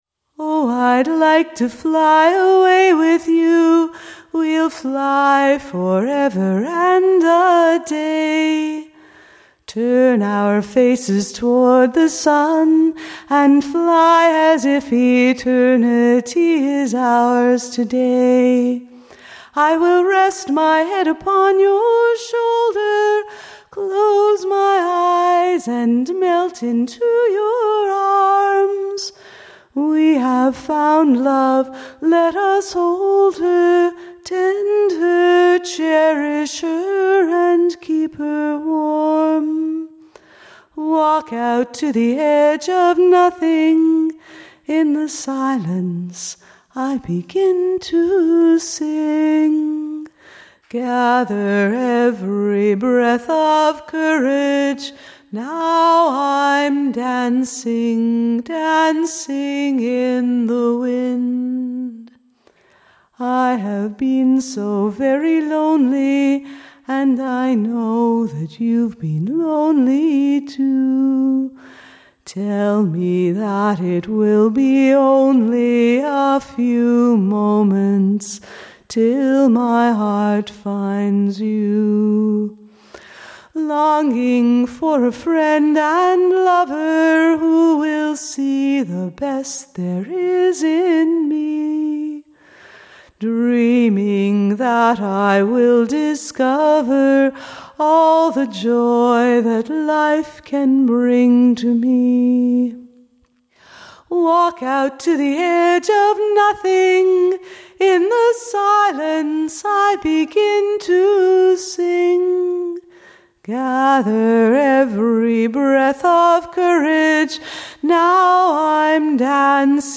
Demo recording
Dancing_in_the_Wind_demo_recording.mp3